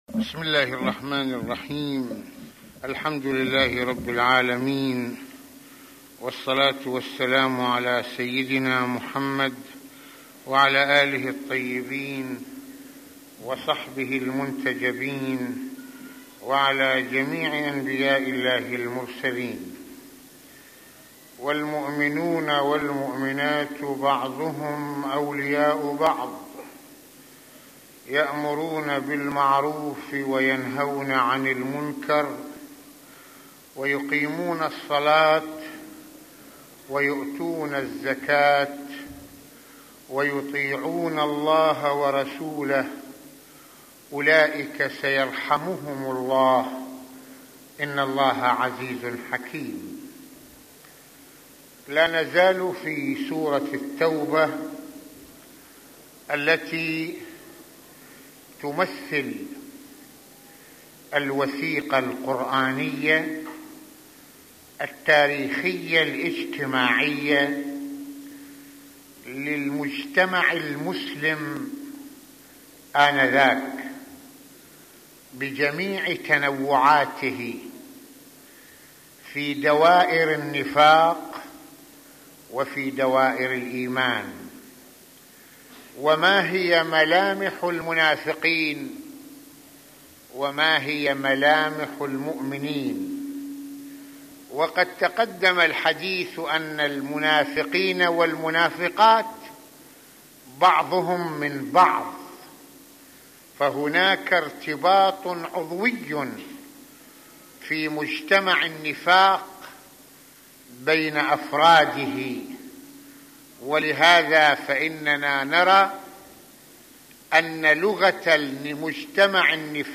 Mohadara_Amer_MAarof_Nahi_Monkar.mp3